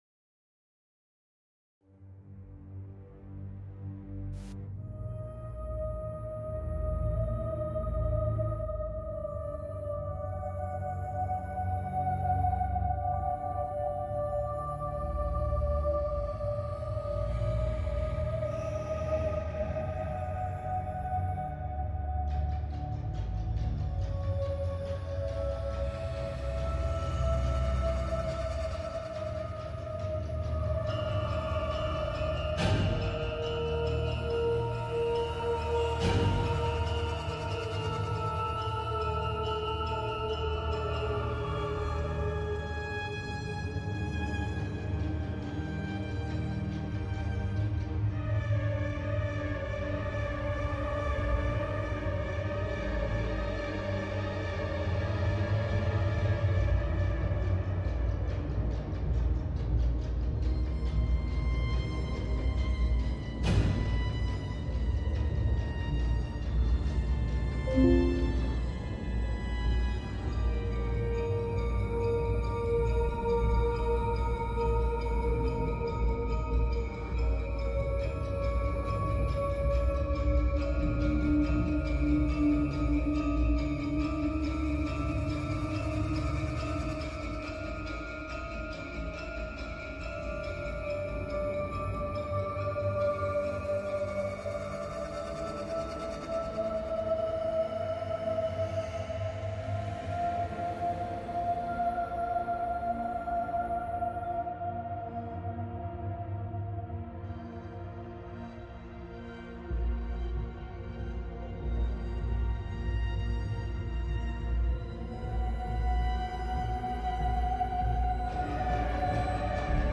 The team shares real investigative experiences, discusses patterns found in shadow person sightings, and explores their possible connection to sleep paralysis, folklore, and the paranormal. Listener questions and live chat comments fuel a deeper conversation, pushing the boundaries of what we think we know about these haunting silhouettes lurking just beyond the light.